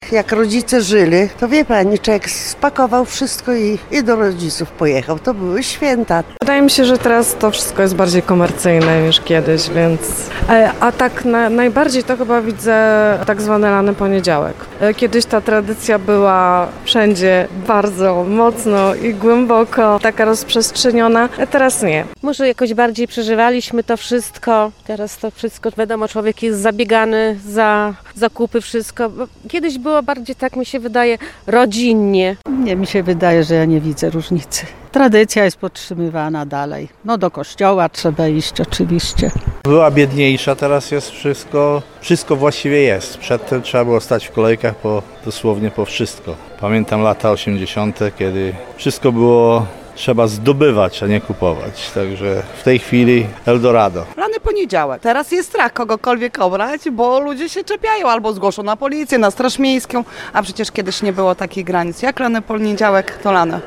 O to spytaliśmy mieszkańców Ełku.